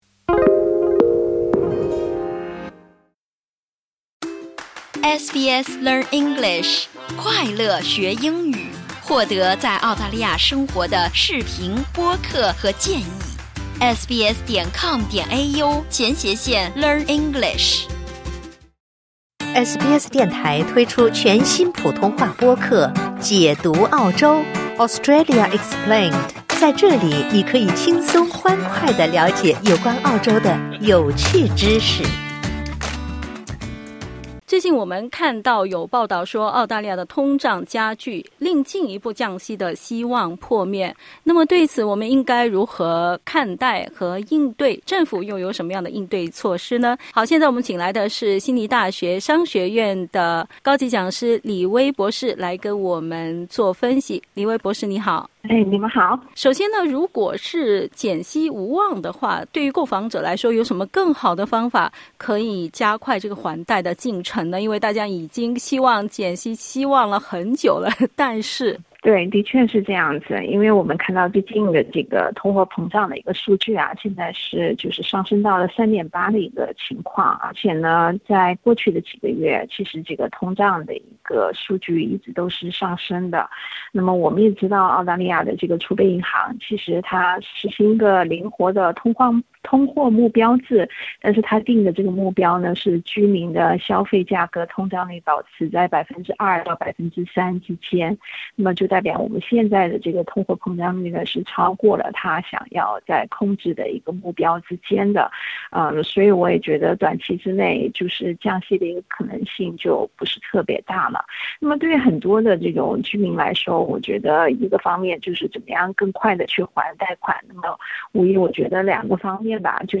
（点击音频收听详细采访） 澳大利亚的通胀加剧，令进一步降息的希望破灭。 如果减息无望，购房者有什么更好的方法可以加快还贷进程？